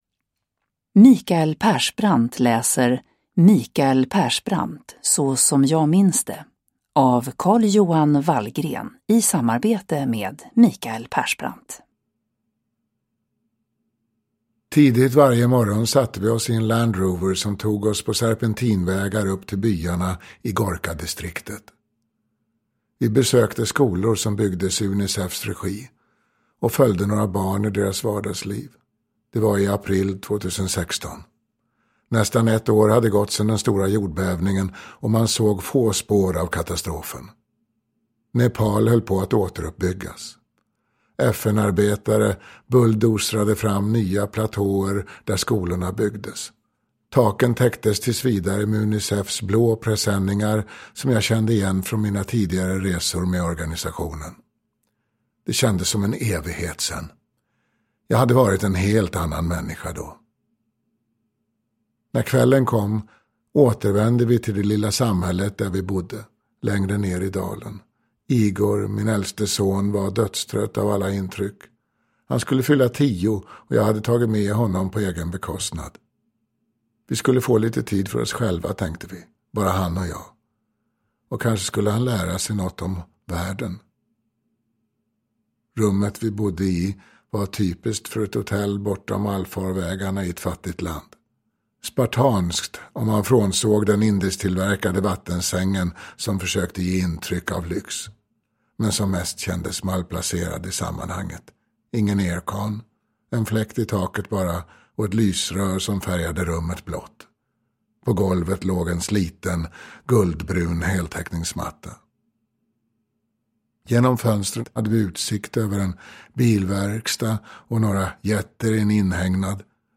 Mikael Persbrandt : Så som jag minns det (ljudbok) av Carl-Johan Vallgren